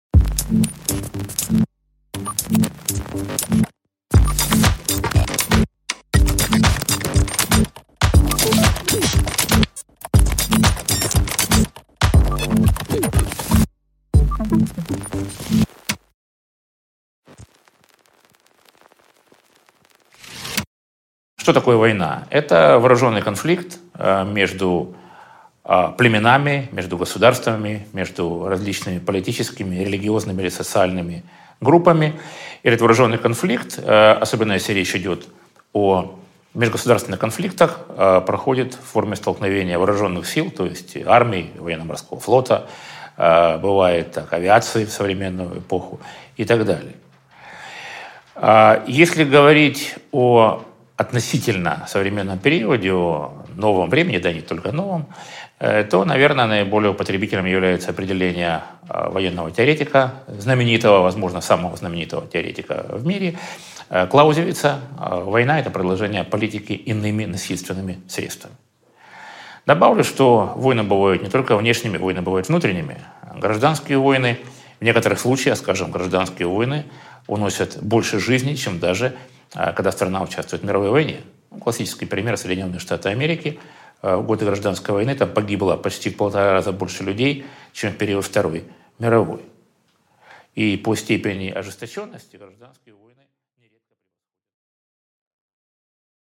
Аудиокнига Курс на ухудшение | Библиотека аудиокниг